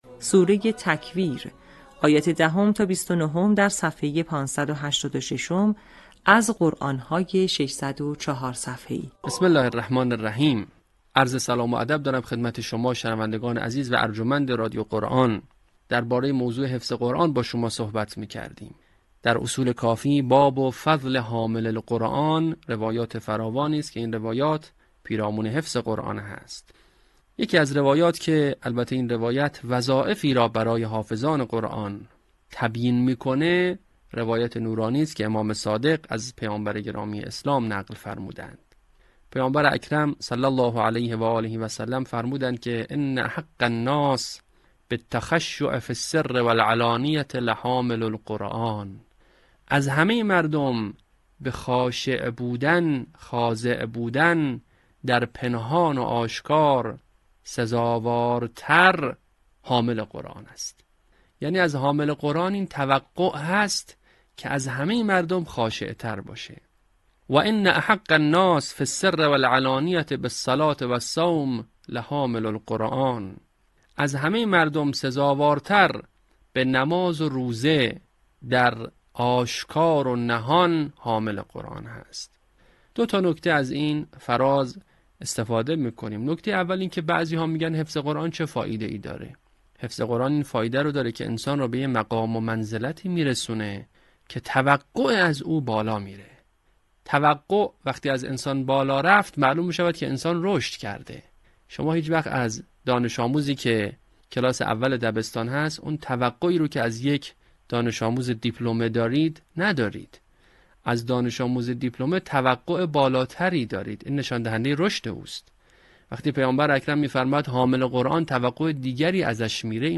صوت | آموزش حفظ جزء ۳۰، آیات ۱۰ تا ۲۹ سوره تکویر